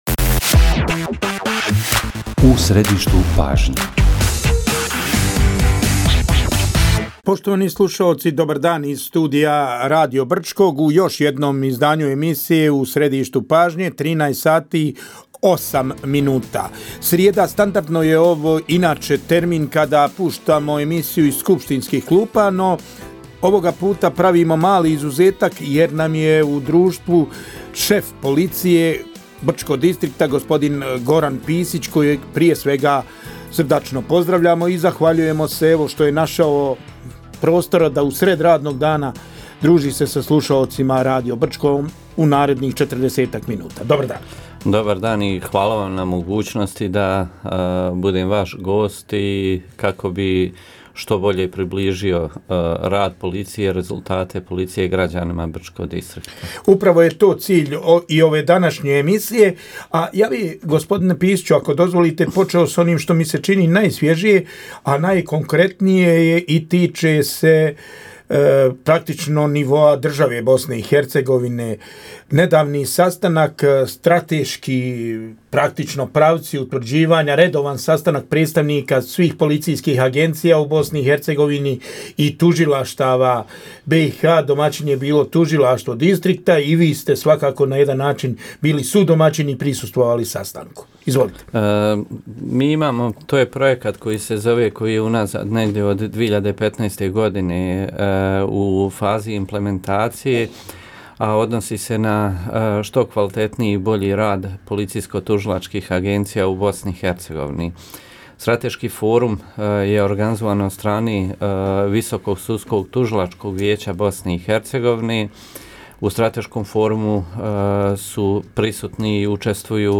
Gost emisije “U središtu pažnje” – šef Policije Brčko distrikta BiH Goran Pisić